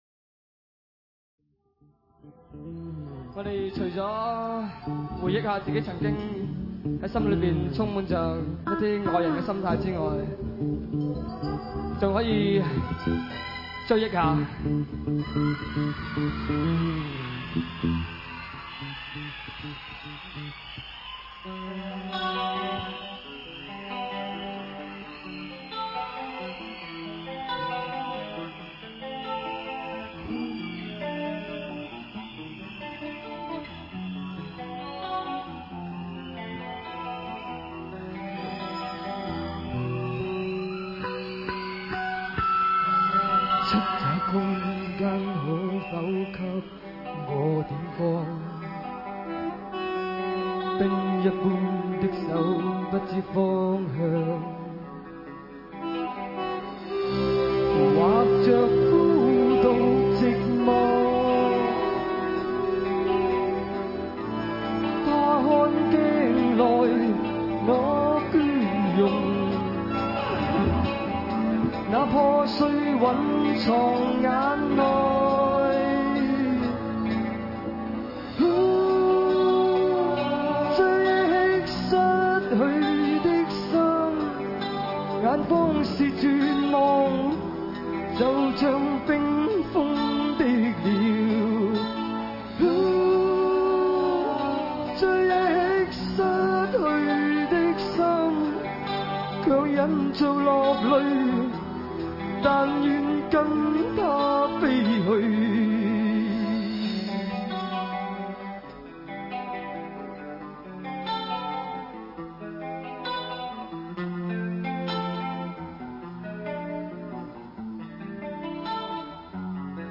现场演绎